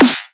UntitledDJTool_Snare.wav